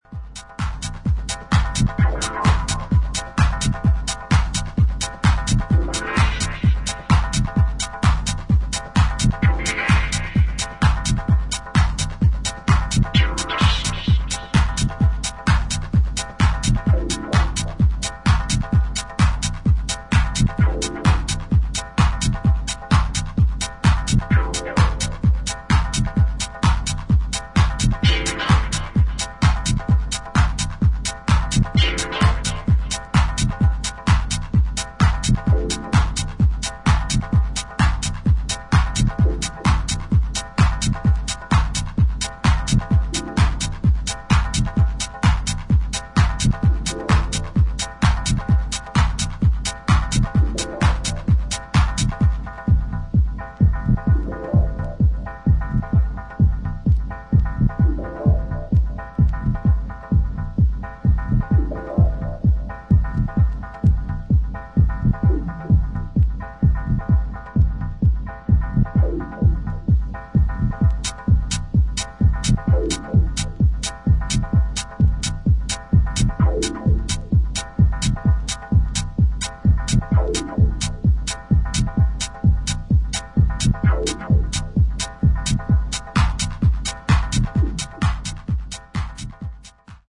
デトロイトテクノへの傾倒を感じる